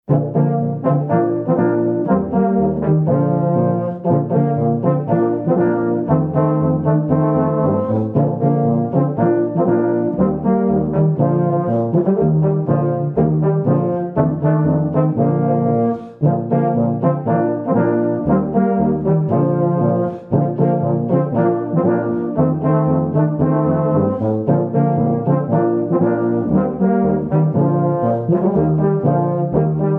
A rousing rendition of the Octoberfest classic
written in four part harmony for tuba.